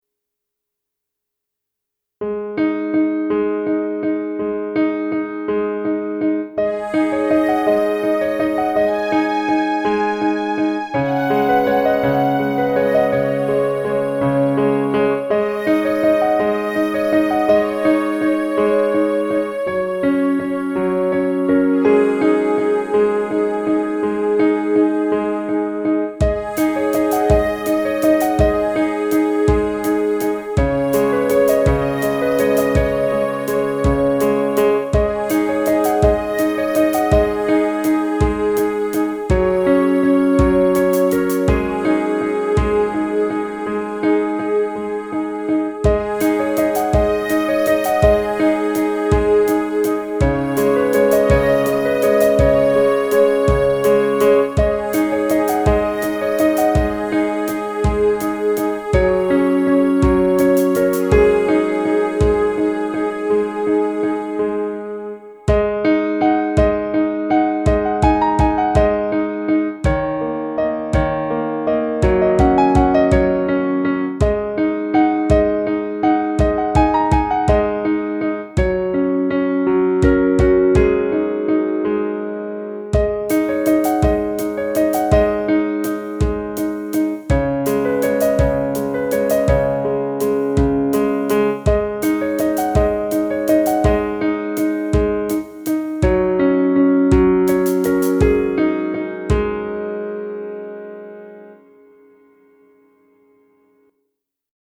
曇天の空を見ていたら月でウサギを見ながら団子でも食べているのか？と思いながら作った短い曲です。　　もしかして一緒に踊っているのかとも思いながら。